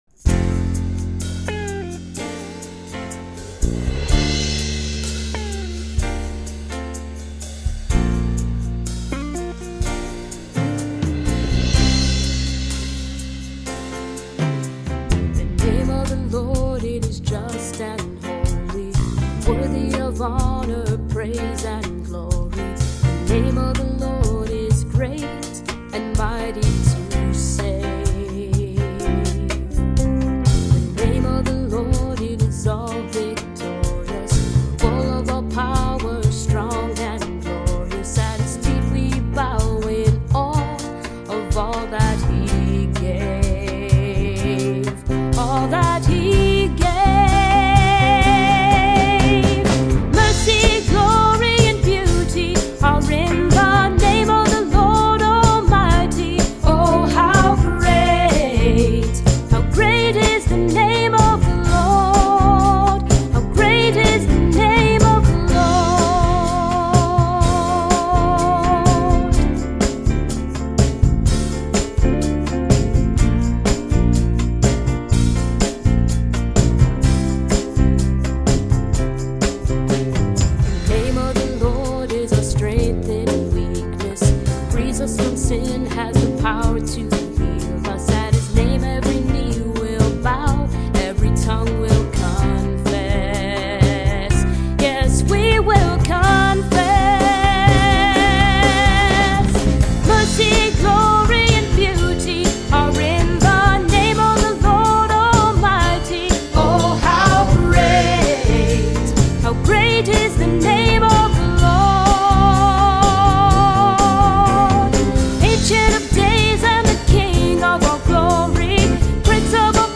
Christian music
praise and worship music